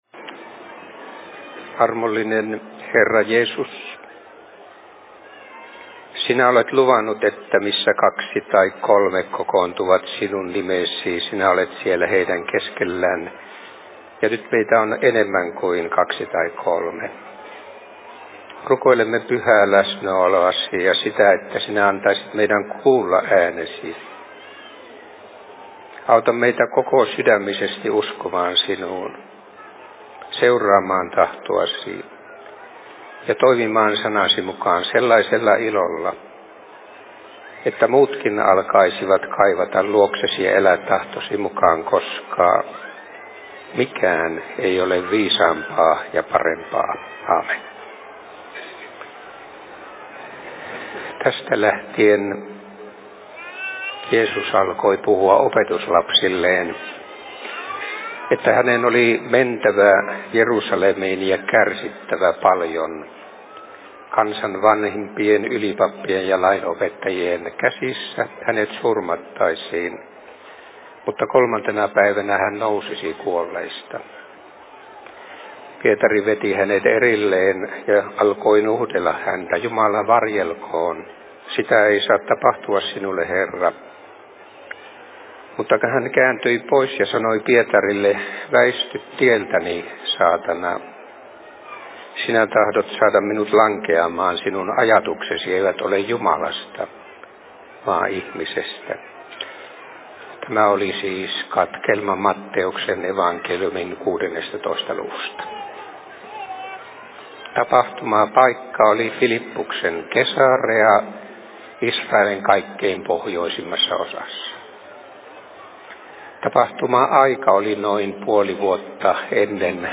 Radiosaarna 2011
Paikka: 2011 Suviseurat Lumijoella